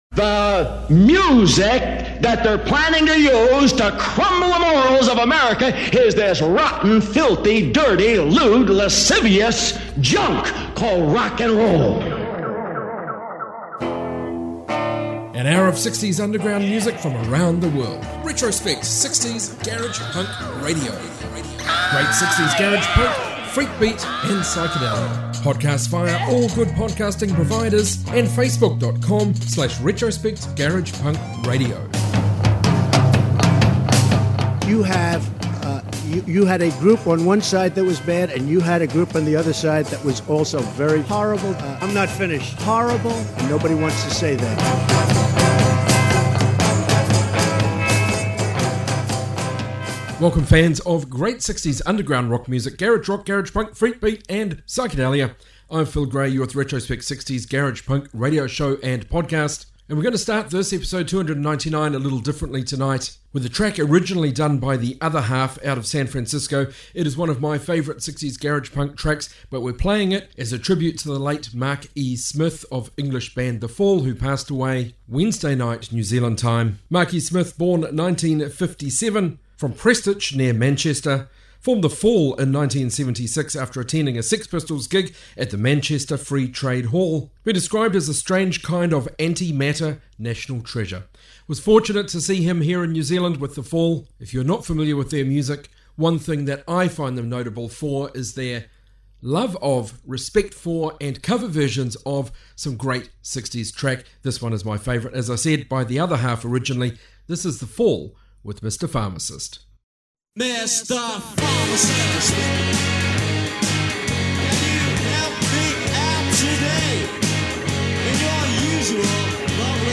60s global garage